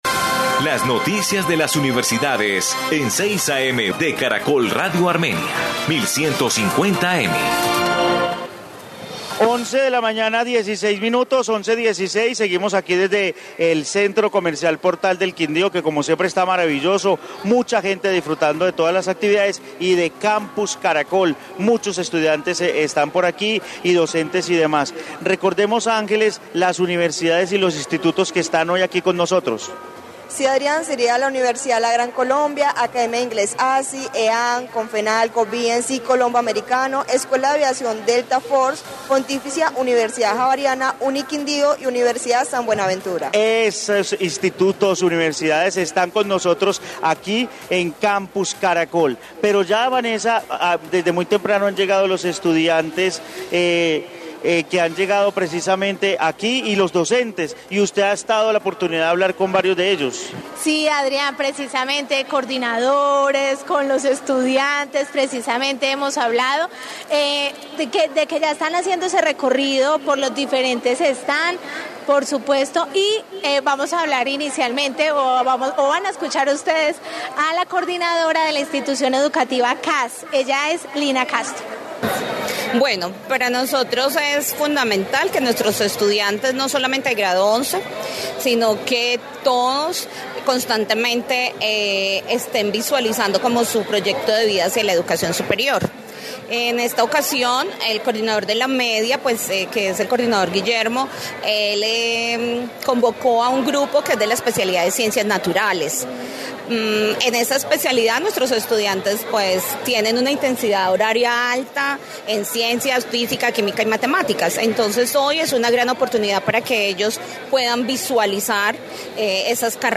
Informe Campus Caracol
Caracol Radio realizó la transmisión del noticiero de mediodía desde este importante espacio donde instituciones educativas hicieron presencia para conocer en detalle las ofertas de las diferentes instituciones de educación superior.